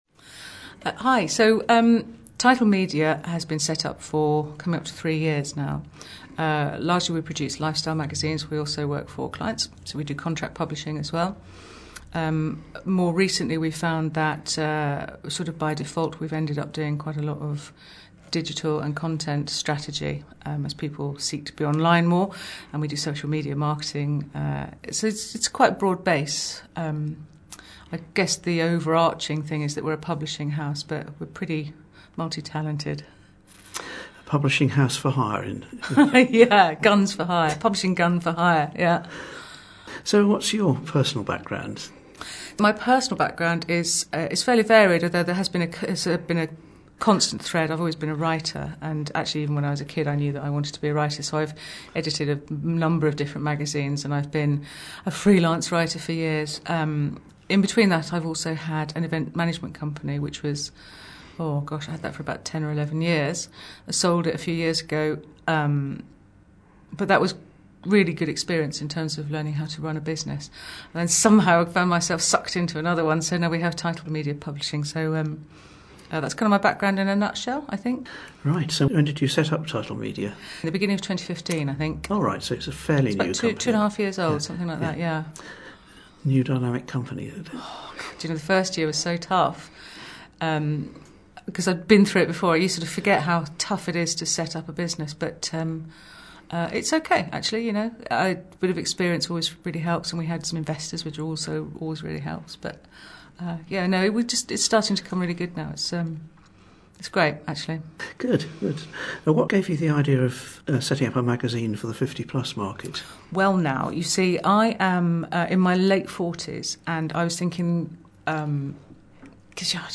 With grateful thanks to Grey Matters who invited us on to their show, and digital Radio Reverb for broadcasting this interview.
silver-magazine-interview-radio-reverb.mp3